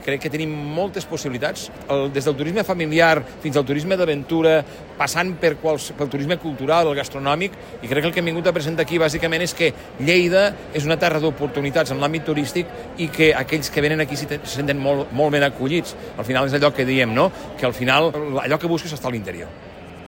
El president de la Diputació de Lleida, Joan Talarn, explica la participació de la corporació a FITUR